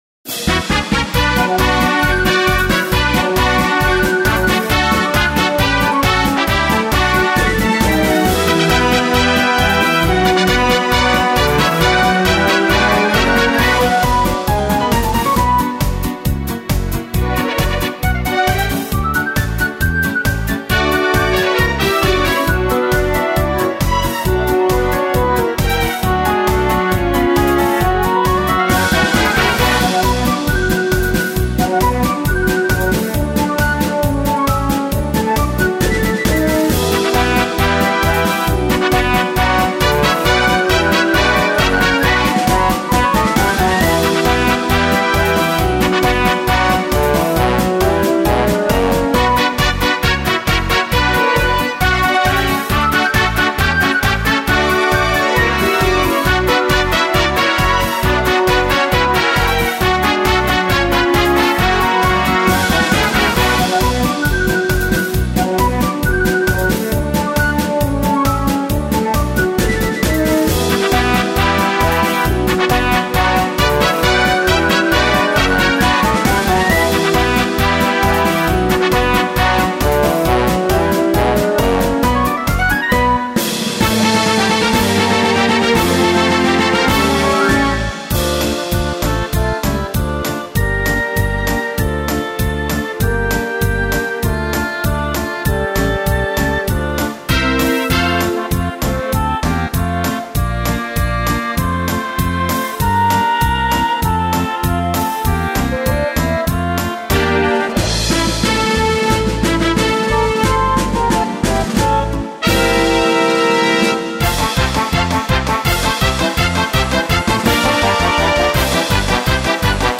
Если совсем по-быстрому, то Edirol Orchestral вполне себе.